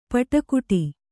♪ paṭa kuṭi